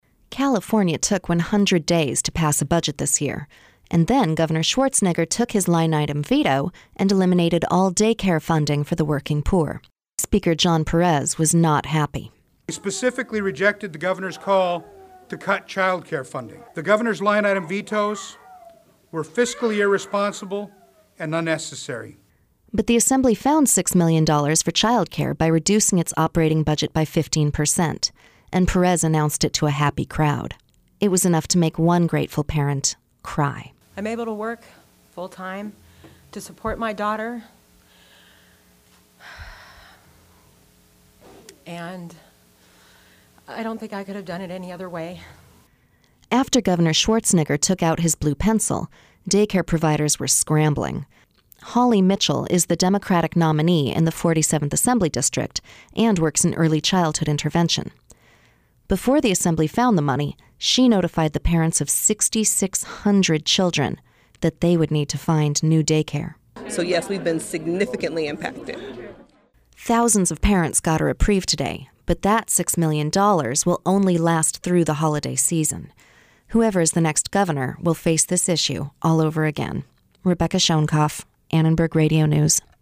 One parent spoke movingly of her worries about finding daycare for her child. In tears as she tried to catch her breath, she said she was no longer on welfare, and she was grateful to be able to work.